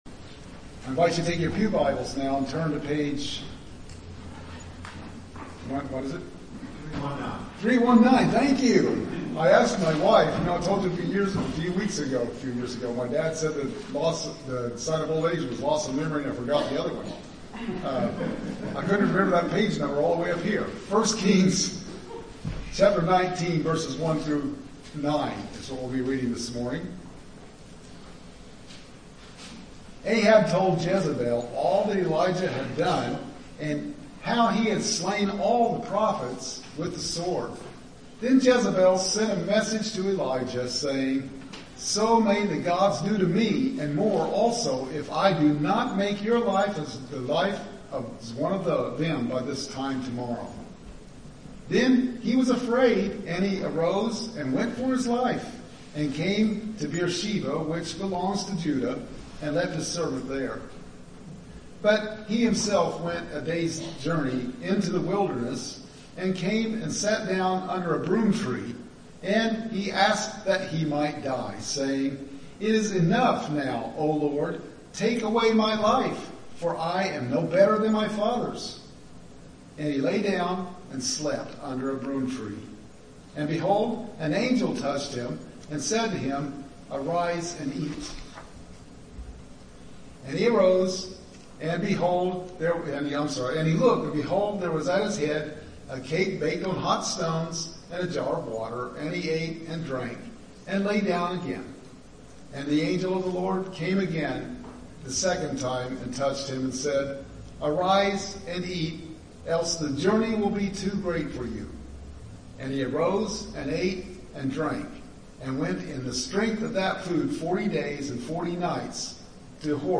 Thank you so much for joining us each week through this worship outreach!